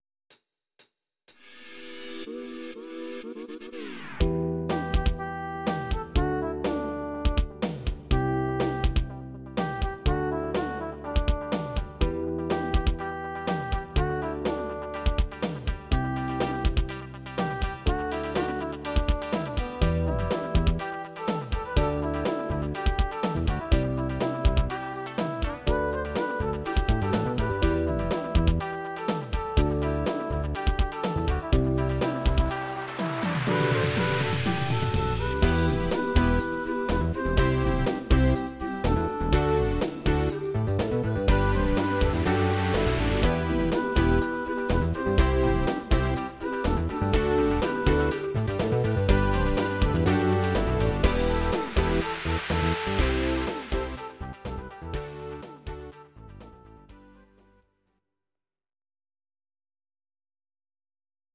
Audio Recordings based on Midi-files
Pop, Disco, 2010s